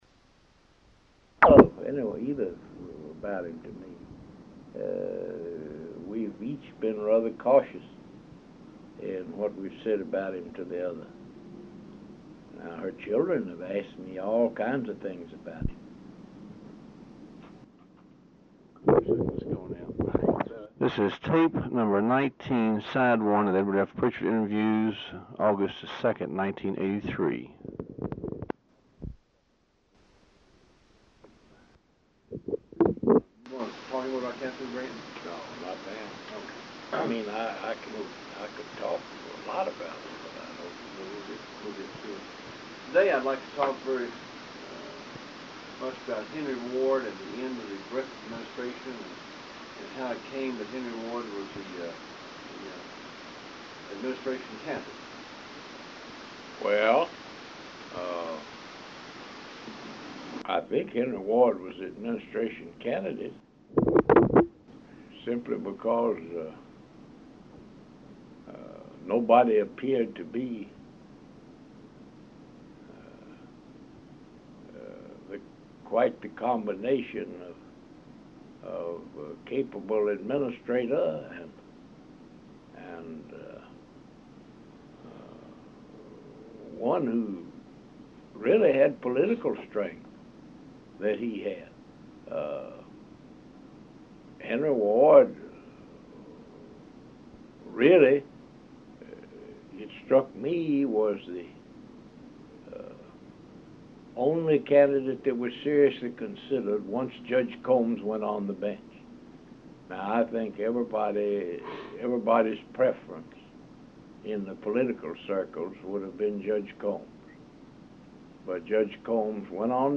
Oral History Interview with Edward F. Prichard, Jr., August 2, 1983 Part 1